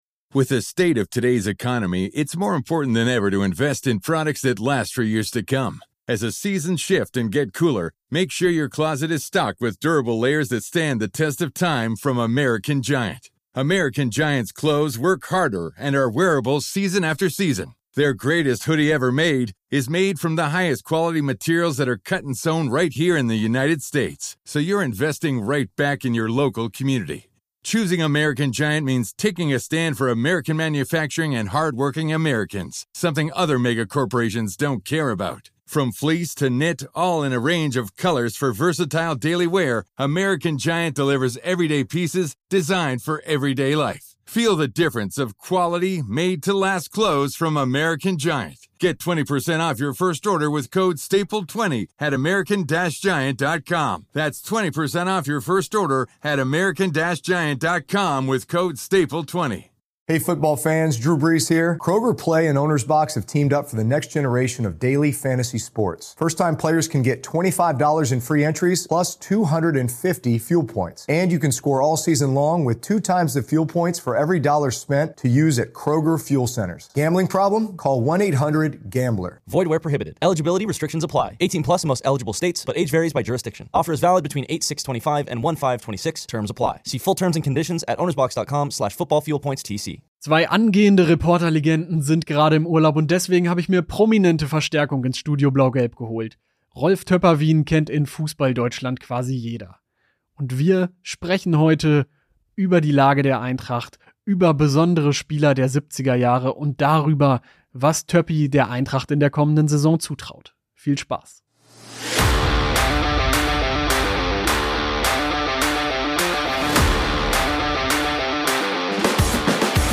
Rolf Töpperwien im Interview: Reporter-Legende wagt mutige Punkte-Prognose ~ Studio Blau Gelb – Der Eintracht-Podcast Podcast